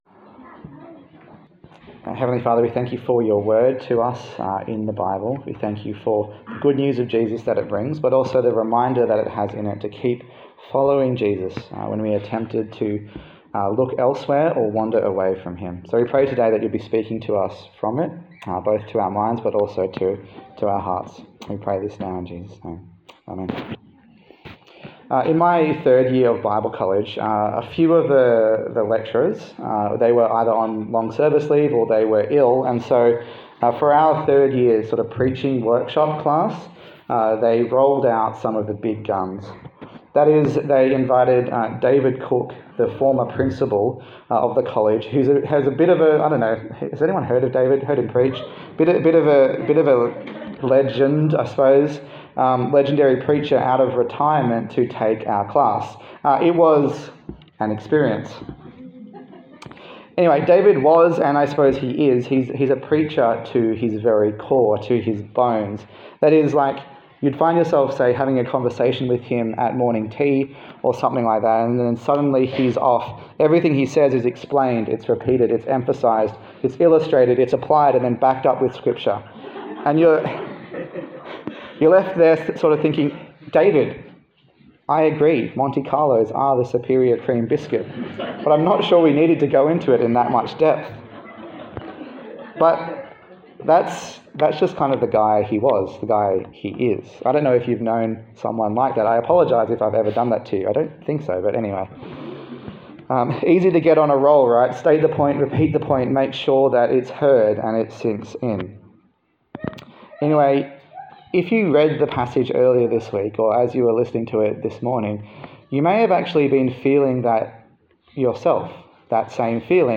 Hebrews Passage: Hebrews 1:5-2:4 Service Type: Sunday Service